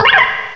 cry_not_sewaddle.aif